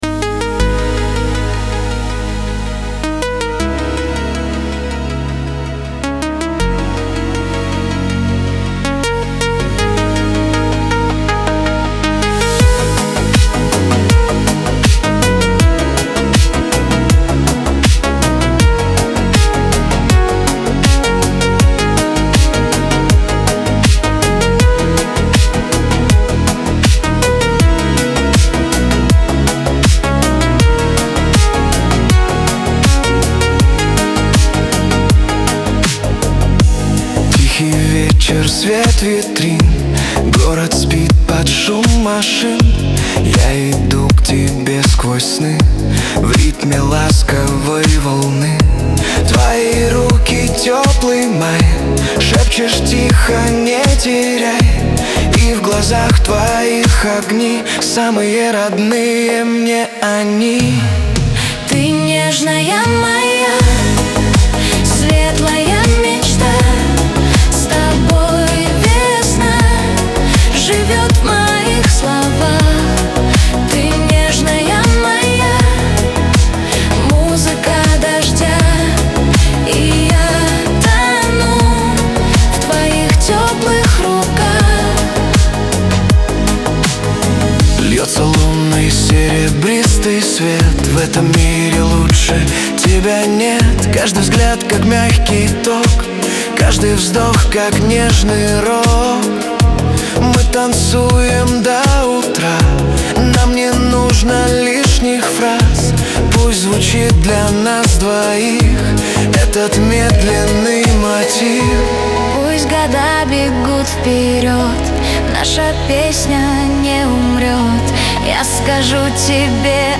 pop
dance
эстрада